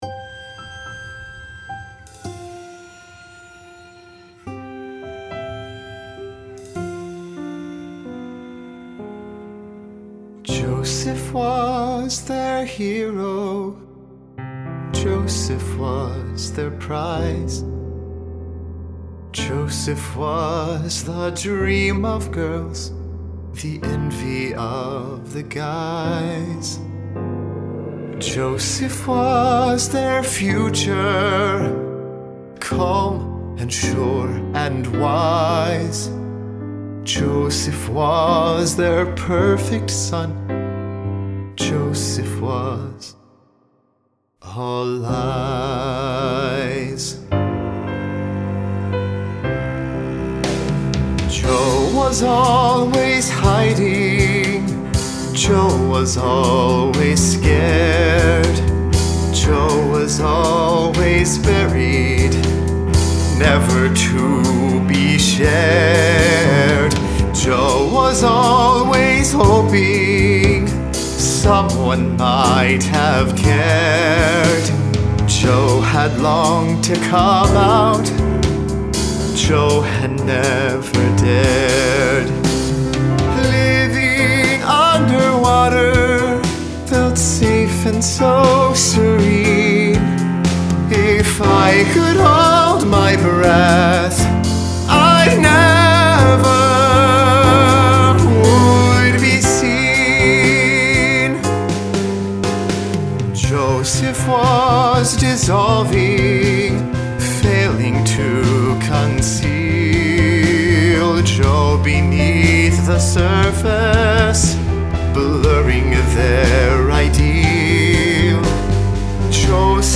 Piano, Cello, Drums, Guitar, Bass
Concept Cast Recording